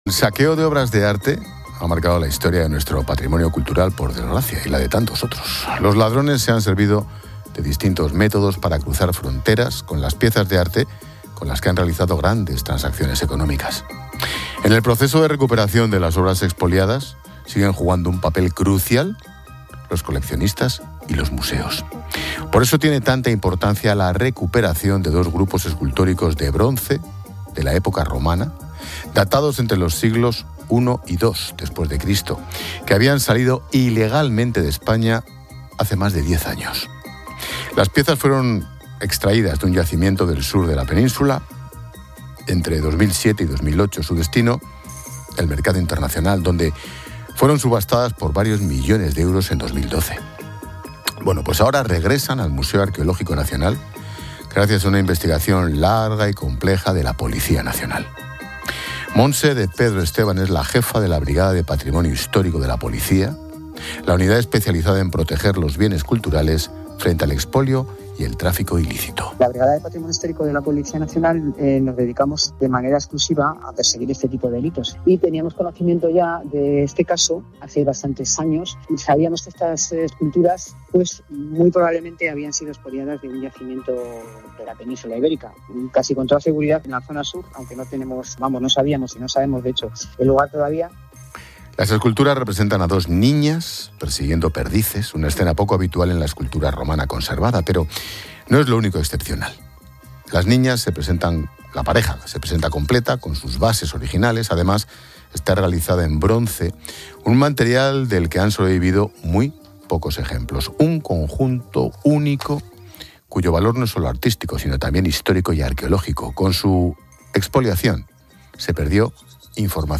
Ángel Expósito cuenta la historia detrás de la recuperación de dos esculturas romanas expoliadas